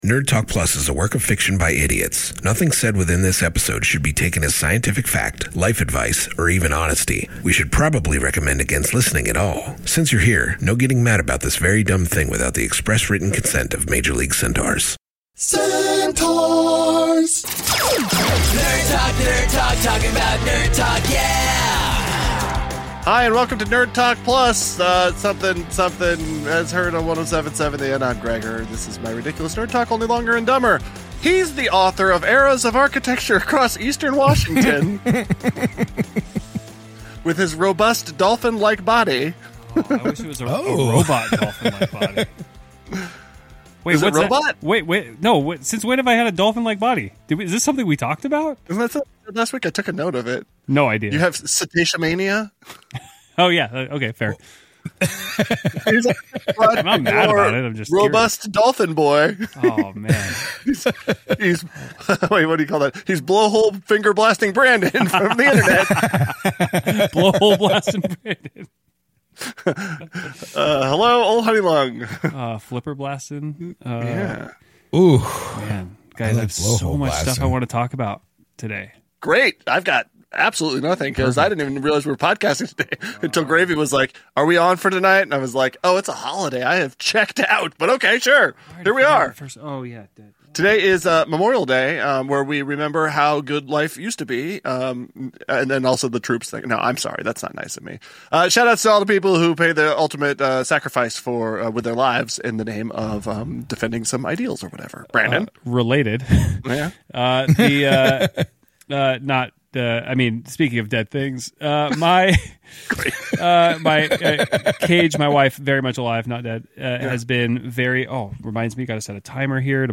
Sorry in advance for the errors editing this, it was terrible. All four idiots are in Spokane for this very special super-pod that is five episodes in one.